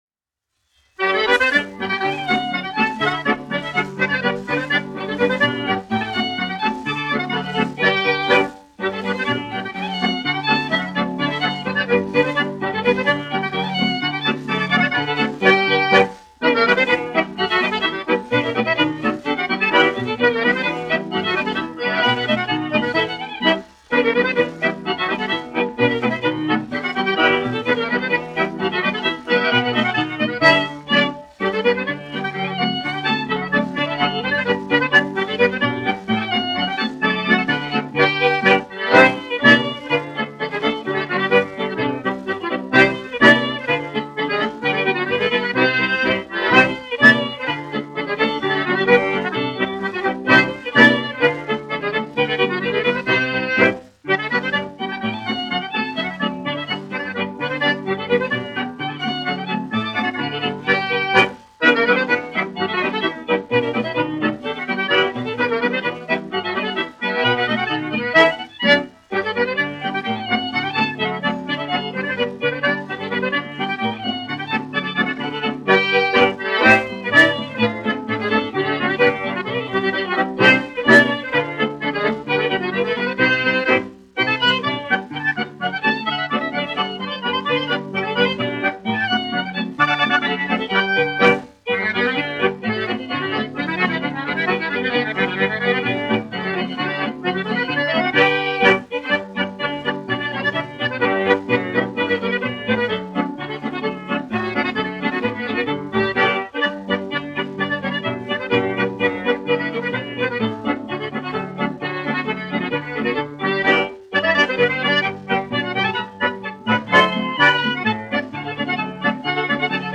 1 skpl. : analogs, 78 apgr/min, mono ; 25 cm
Polkas
Populārā instrumentālā mūzika
Skaņuplate